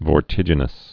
(vôr-tĭjə-nəs)